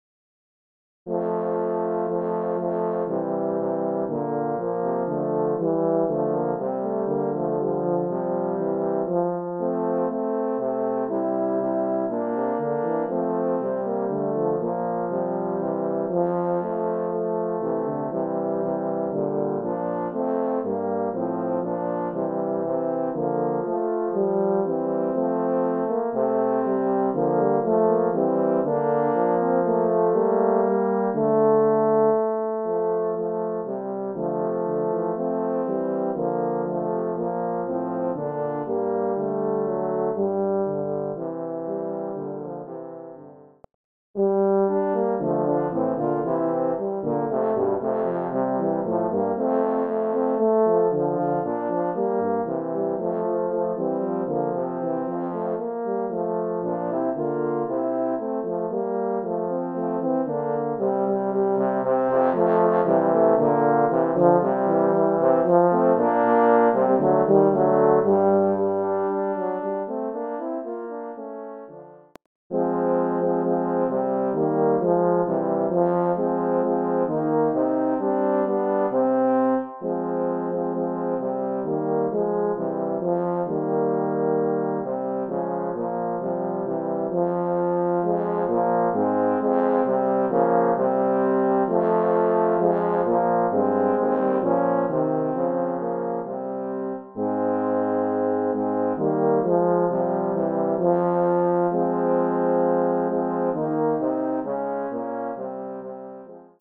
Voicing: Horn Trio